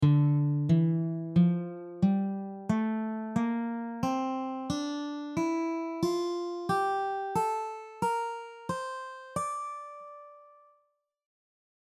Each scale below will cover two octaves on the guitar in standard notation with tabs and audio examples included.
D minor scale
The notes of the D natural minor scale are D, E, F, G, A, Bb, and C.
D-minor-Dm-scale-audio.mp3